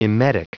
Prononciation du mot emetic en anglais (fichier audio)
Prononciation du mot : emetic